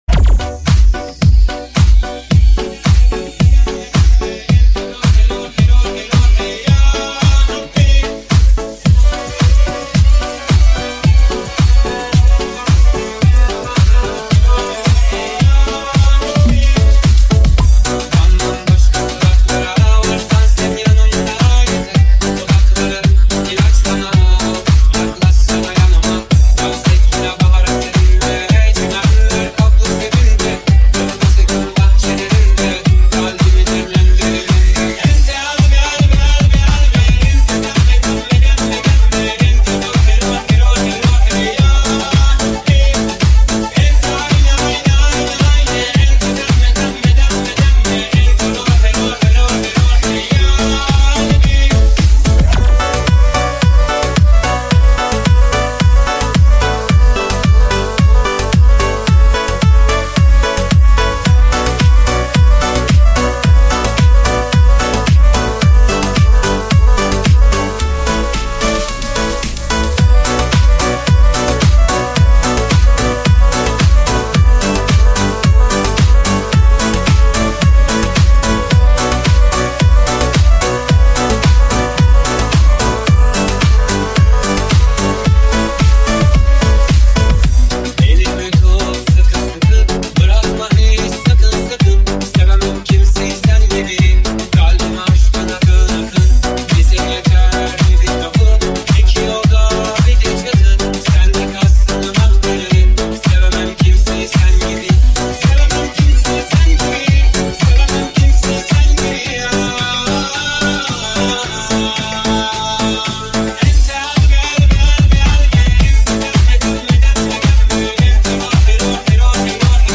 EDM Remix